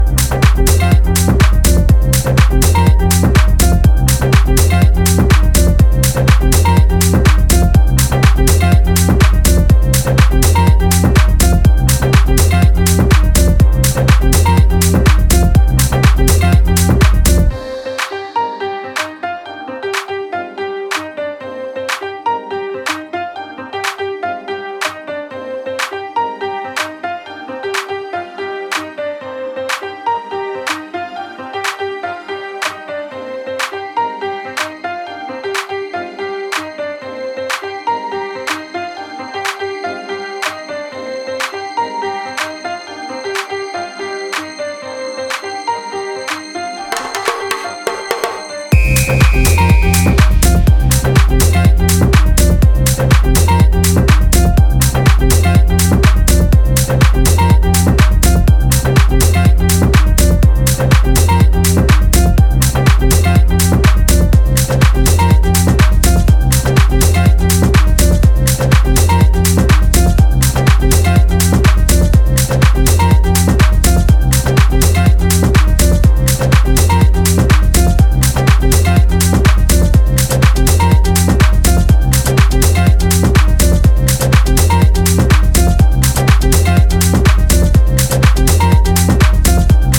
ピアノリフがオーセンティックなイタリアン・ハウスを思わせる